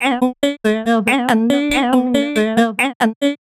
Index of /90_sSampleCDs/Sample Magic - Transmission-X/Transmission-X/transx loops - 140bpm